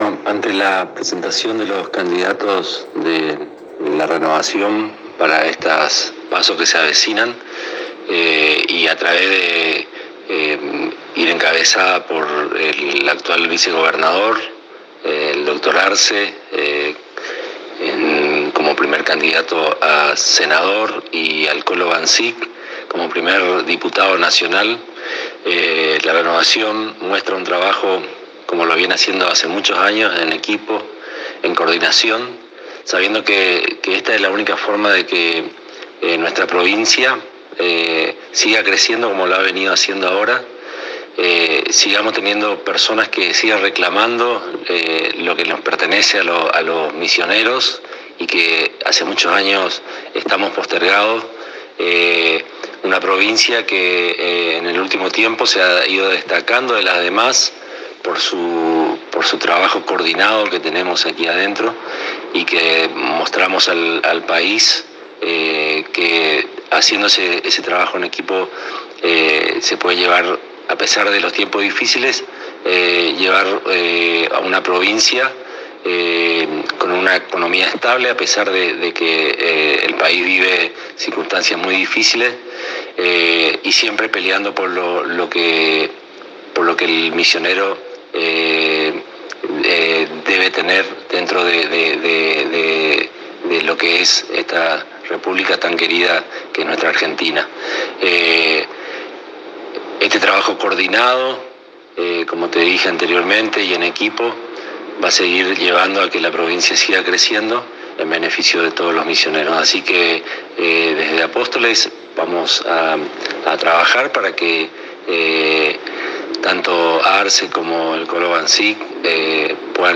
El Presidente del H.C.D. de Apóstoles, Alberto Daniel «Tito» Poliszuk, en declaraciones exclusivas a la ANG, aseguró que la lista de candidatos a Legisladores Nacionales del Frente Renovador en Innovación son la síntesis del sentir misionerista e irán a la Nación a llevar la experiencia de Misiones para el bienestar de la Patria toda y defenderán al pueblo y Gobierno de Misiones.